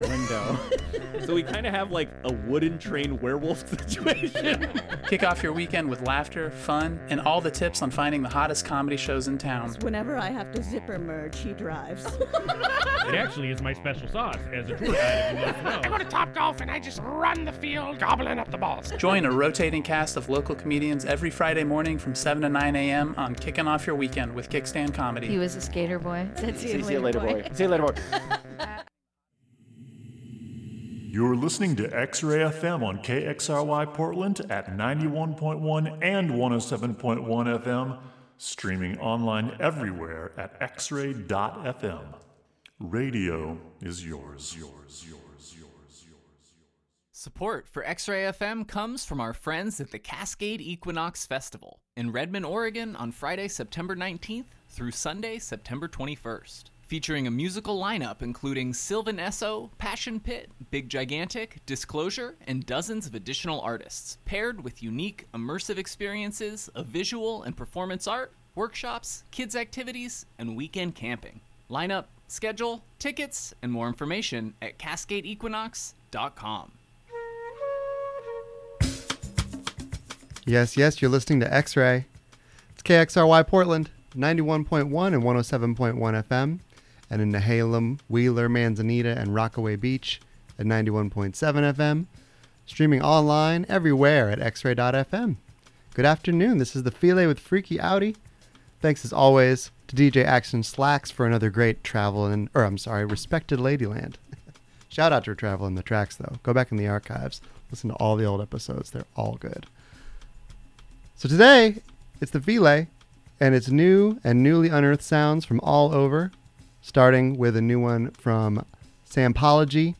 New and newly unearthed jams from all over to put you in a dancing mood.